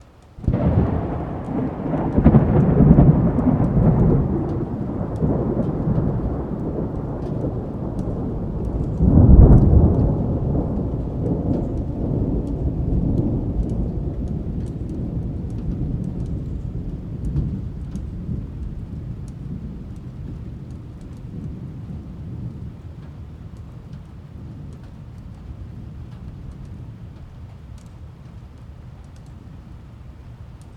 ambient-thunder-clap-distant-with-rain-mono.ogg